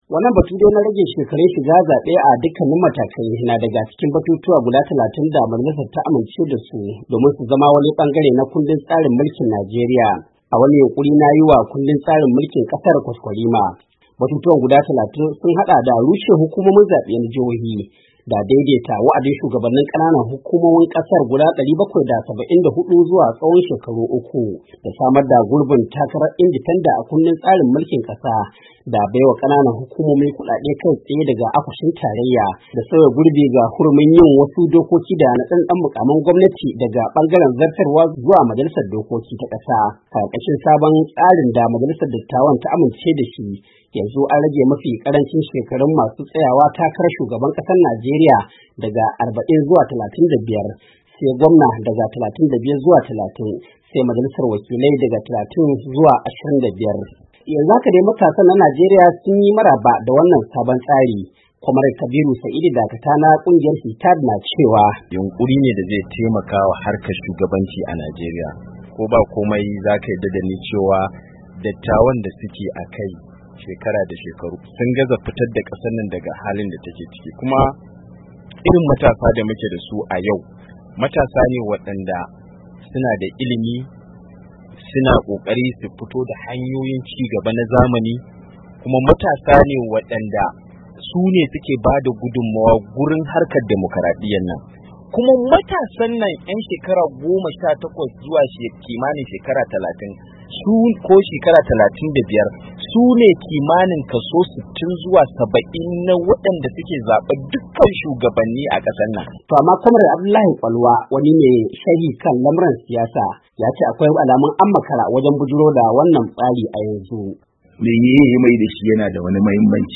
WASHINGTON D.C. —